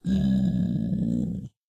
Minecraft Version Minecraft Version 1.21.5 Latest Release | Latest Snapshot 1.21.5 / assets / minecraft / sounds / mob / zombified_piglin / zpig2.ogg Compare With Compare With Latest Release | Latest Snapshot